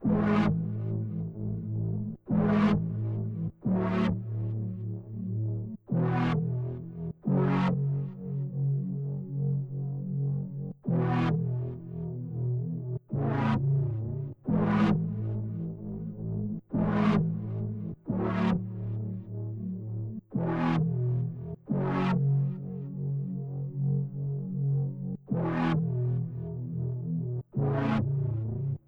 Drake Type Loop 2.wav